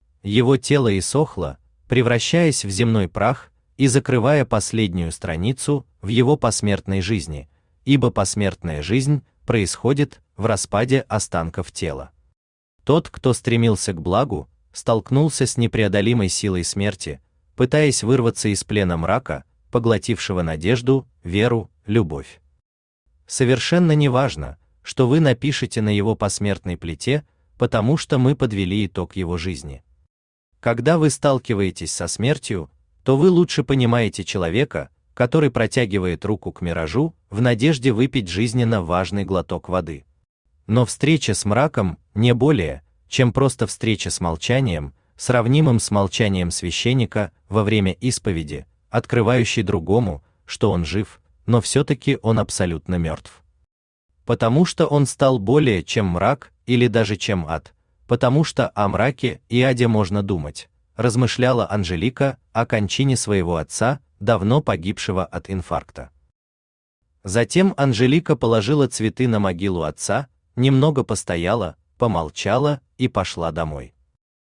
Aудиокнига Назови её по имени… Автор Виталий Александрович Кириллов Читает аудиокнигу Авточтец ЛитРес.